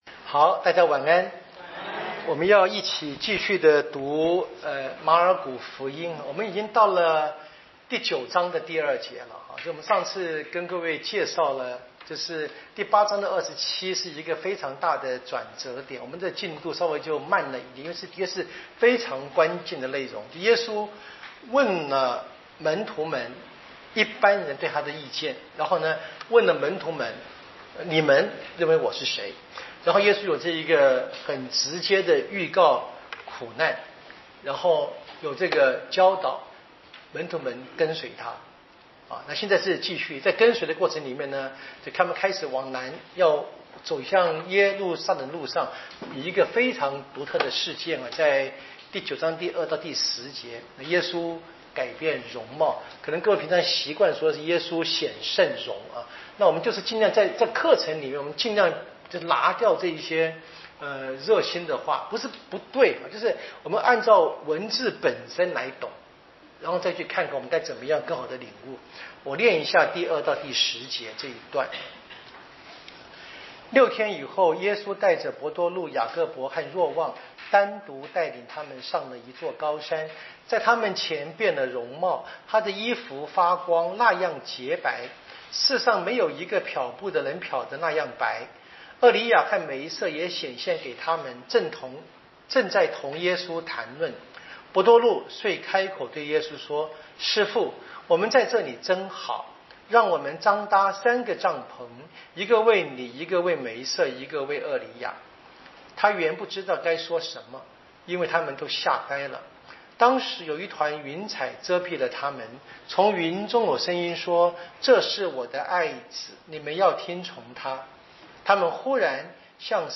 【圣经讲座】《马尔谷福音》